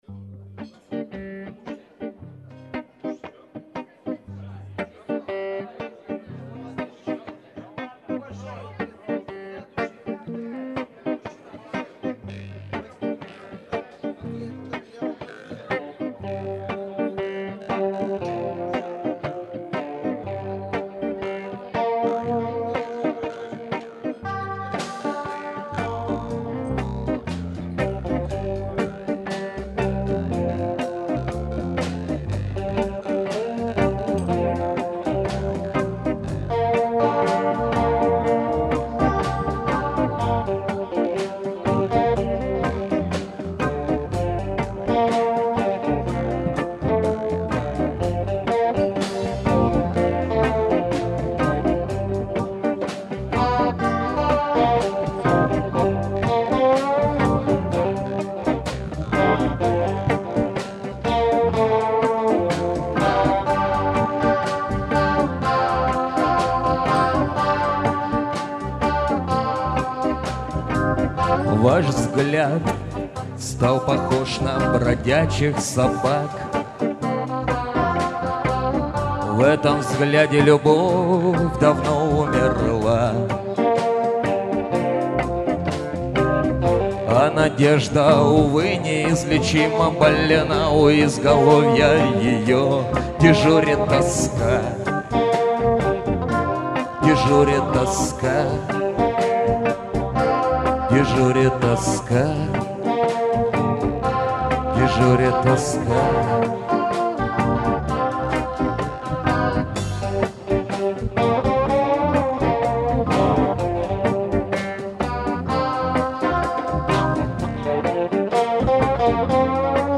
Live in ЦИКЛОП (Тольятти) 24.09.2004
пение, ритм-гитара
соло(саунд)-гитара
барабаны
бас
клавишные
шанкобыз
конги